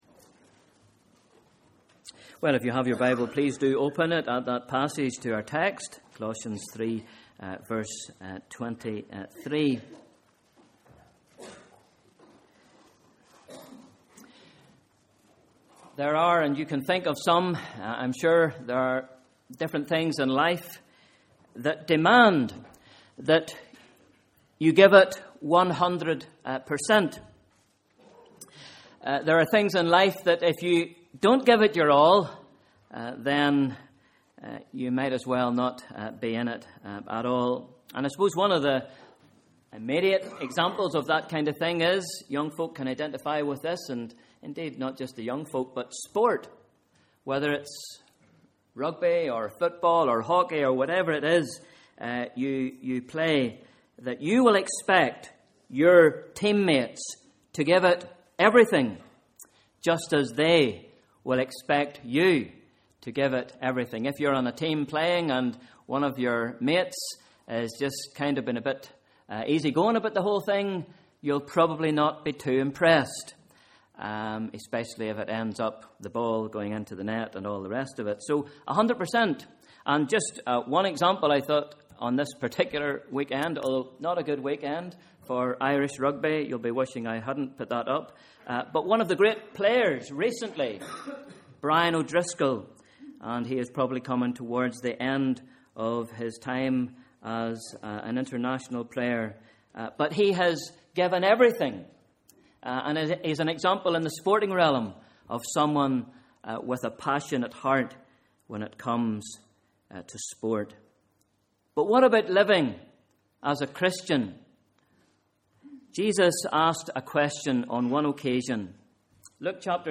Sunday Morning Service: Sunday 17th March 2013